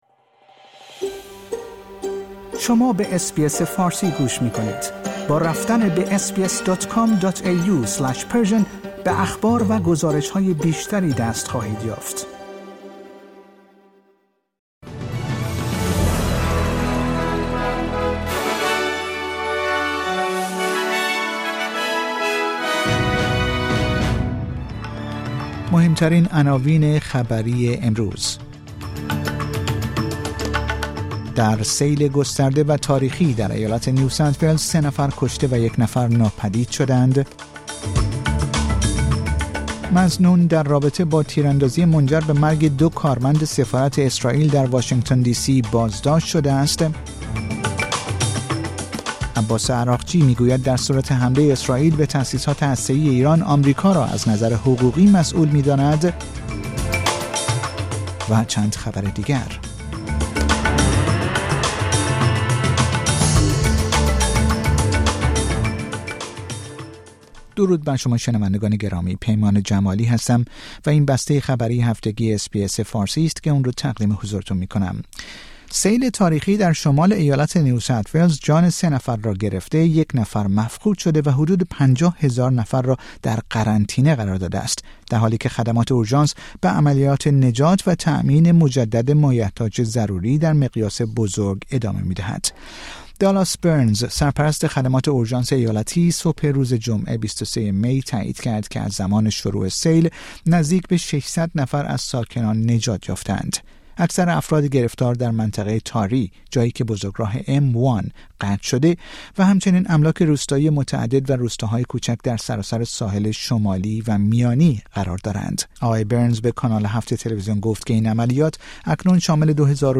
در این پادکست خبری مهمترین اخبار هفته منتهی به جمعه ۲۳ مه ارائه شده است.